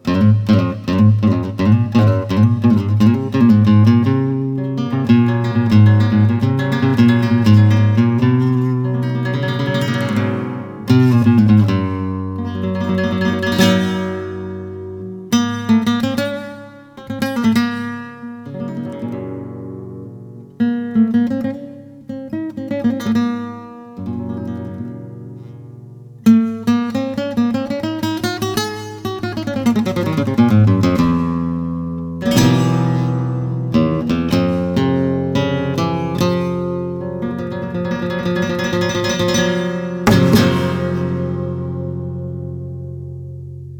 Piconeras. Toque de guitarra. Flamenco.
guitarra
melodía